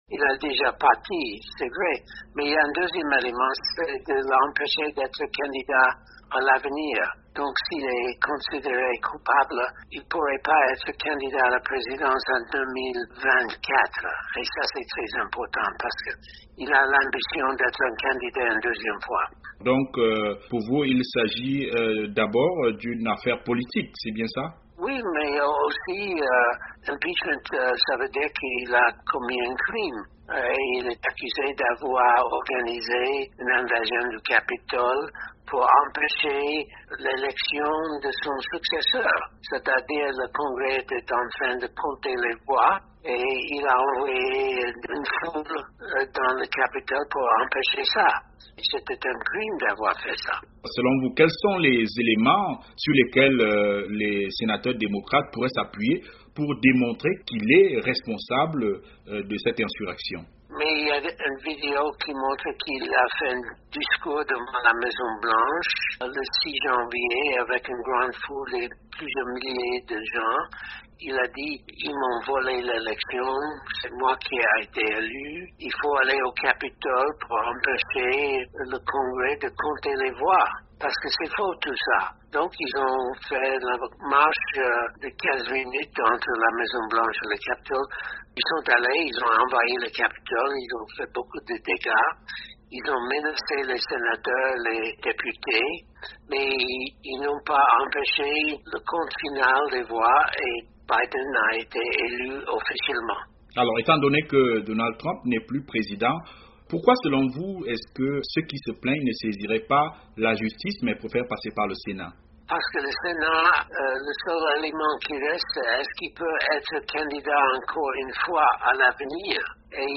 Voici l’analyse de Herman Cohen, ancien secrétaire d’État adjoint en charge des affaires africaines.